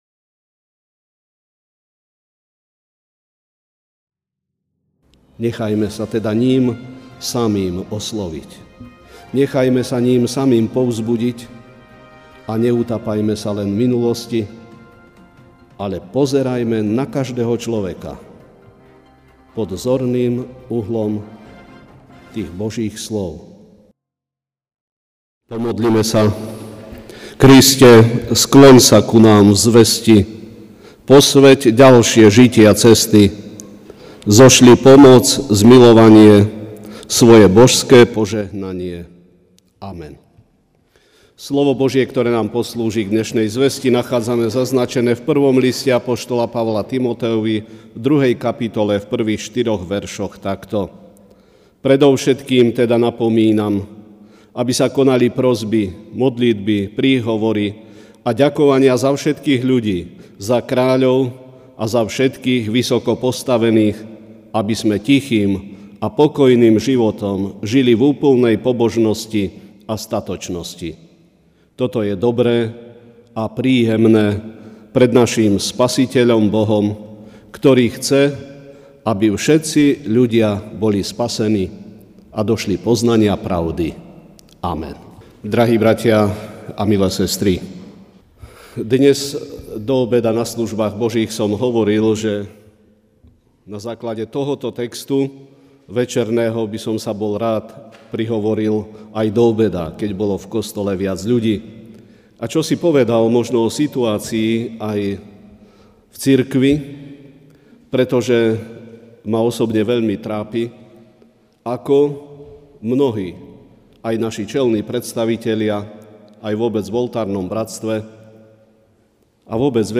Večerná kázeň: Život spásy (1 list Timotejovi 2,1-4)